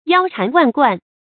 注音：ㄧㄠ ㄔㄢˊ ㄨㄢˋ ㄍㄨㄢˋ
腰纏萬貫的讀法